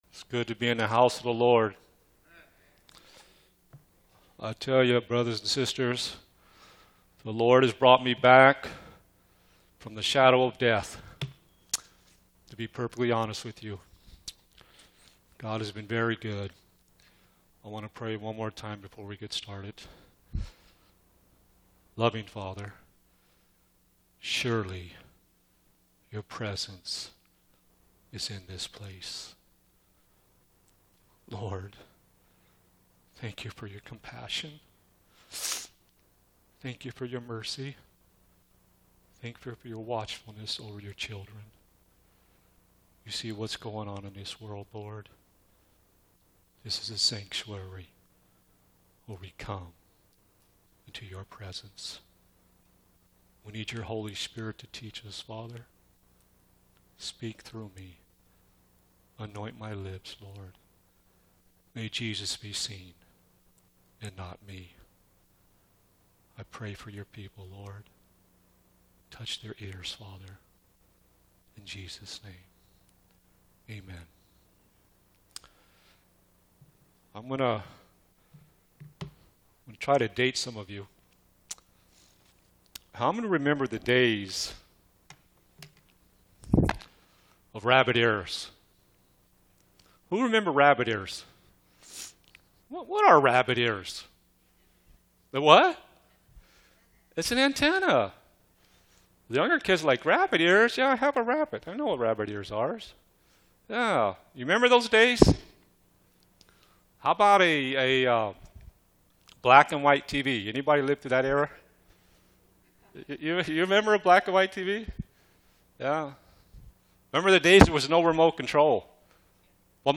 Seventh-day Adventist Church
Sermons